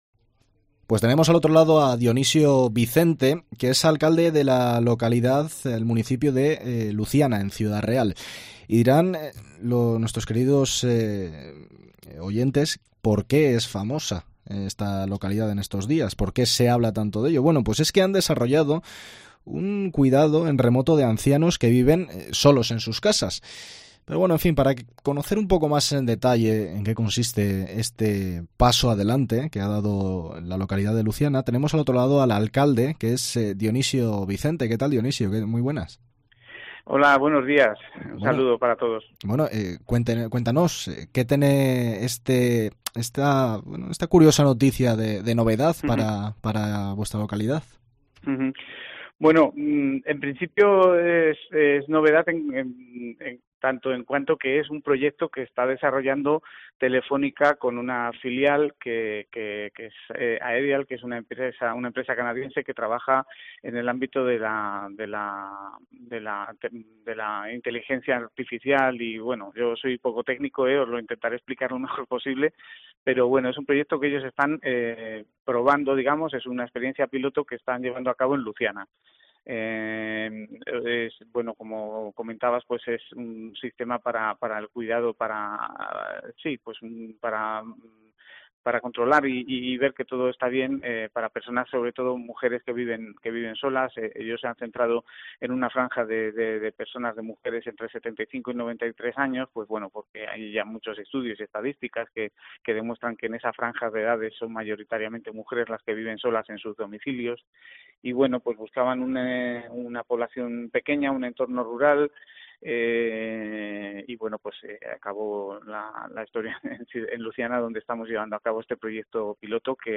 El alcalde de la localidad, Dionisio Vicente, ha pasado por la Cadena COPE para contar más detalles sobre este proyecto, y ha explicado que el programa está destinado a "controlar el bienestar de los mayores entre 75 y 95 años de edad".